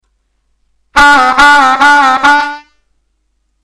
Funkin’ it up on the blues harmonica